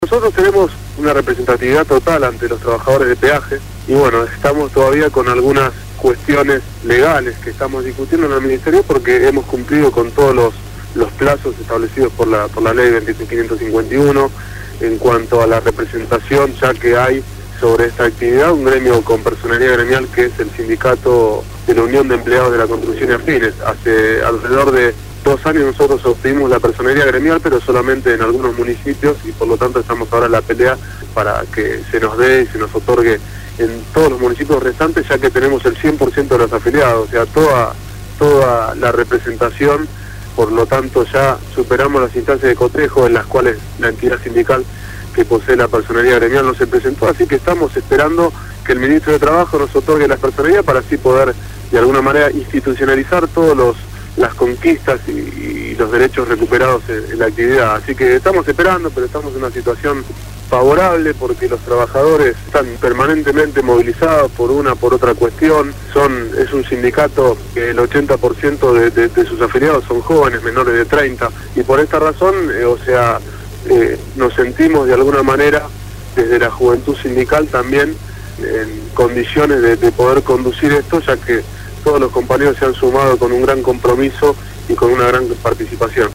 Facundo Moyano, Secretario General del Sindicato Unico de Trabajadores de Peajes y Afines (SUTPA) fue entrevistado en «Voces Portuarias» (Martes